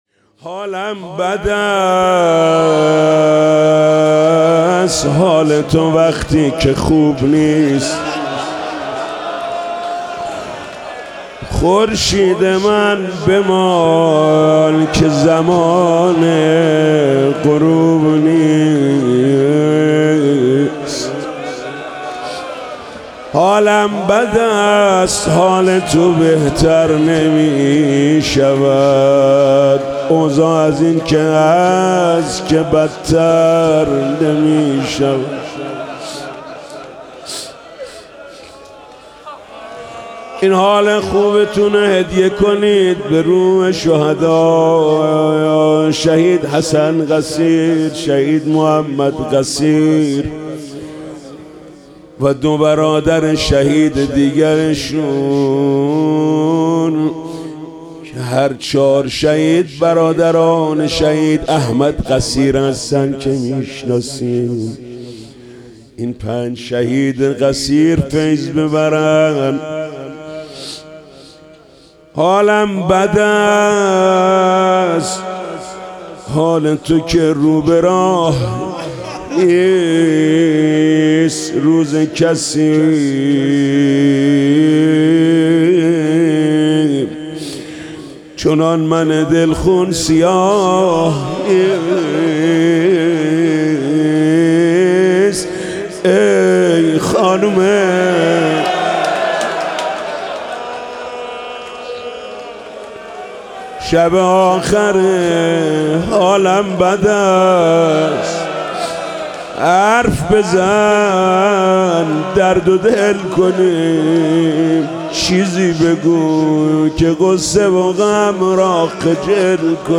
روضه شب چهارم فاطمیه 1403